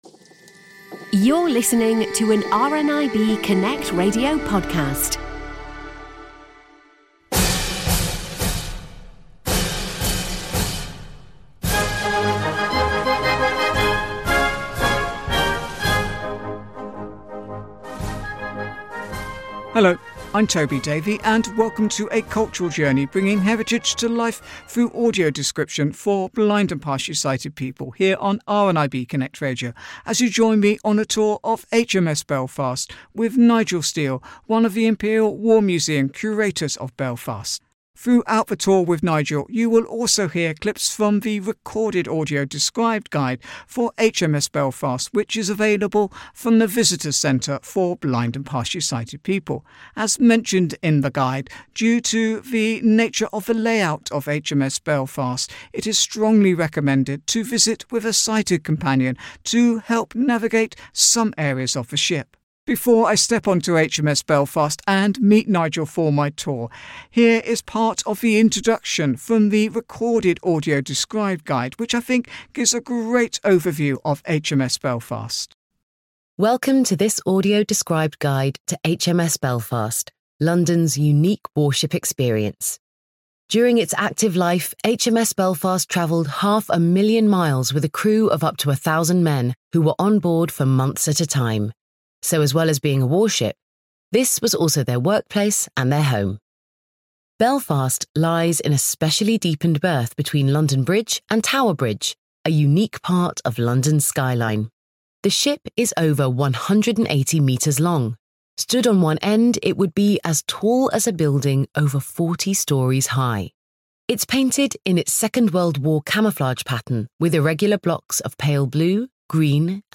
During the tour you will also hear clips from the recorded audio described guide for HMS Belfast which is available from the Visitors Centre for blind and partially sighted people.